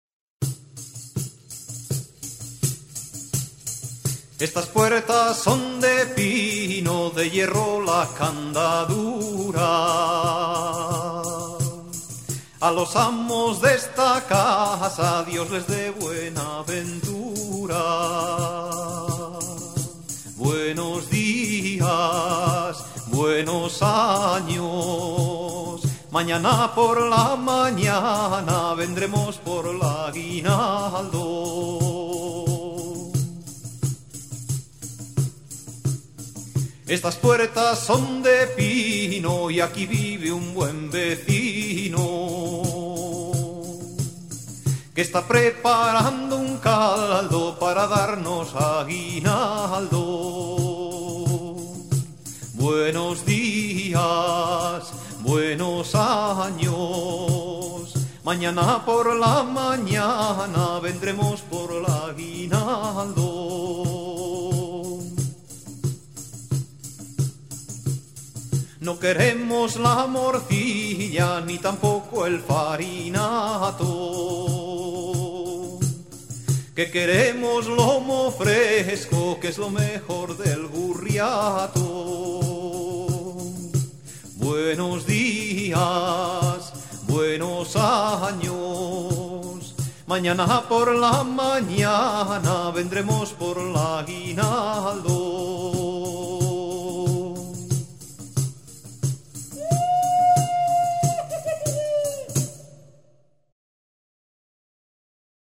Pandereta